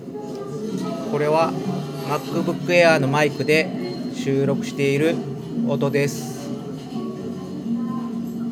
検証方法は、カフェの雑音をかなり音量大きめに流しつつ喋った声を収録してそのままアップロード。
まずは、MacBook Airのマイクでそのまま録った場合。
十分聞き取れる範囲ですが、マイクが遠いのでかなり周囲の音を拾ってしまっているのがわかります。